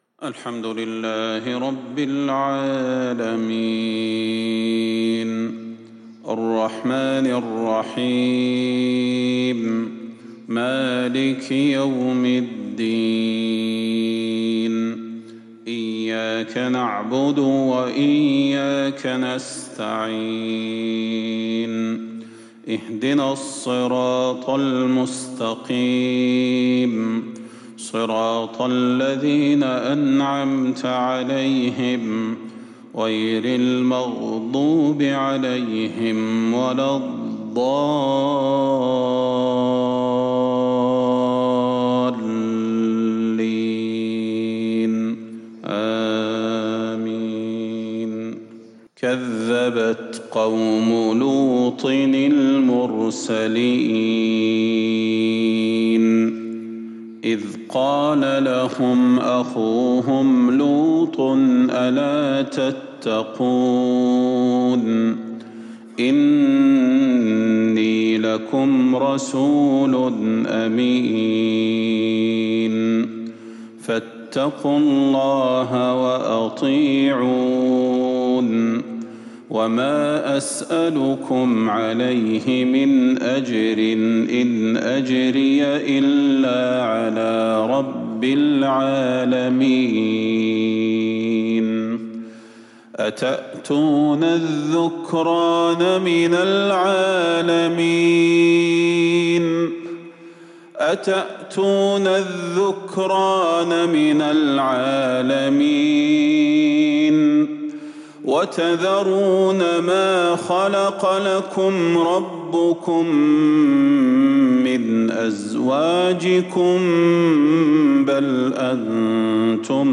صلاة الفجر للقارئ صلاح البدير 24 ربيع الآخر 1442 هـ